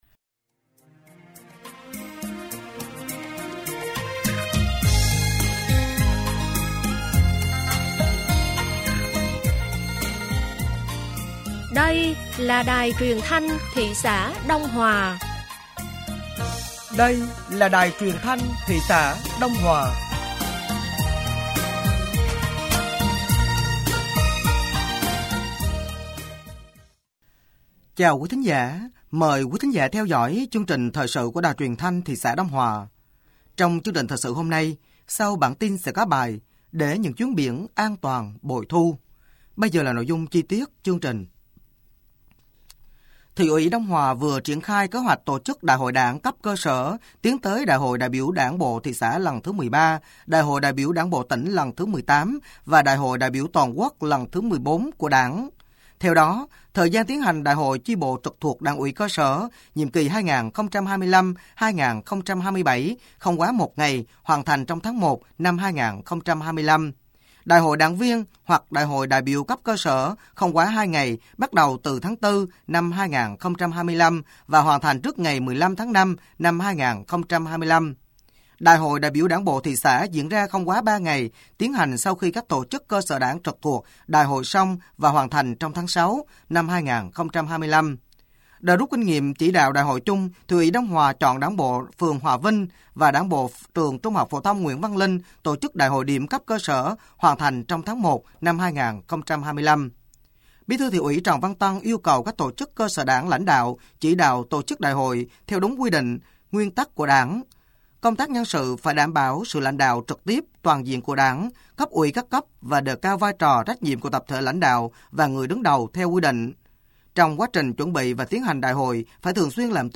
Thời sự tối ngày 22 và sáng ngày 23 tháng 8 năm 2024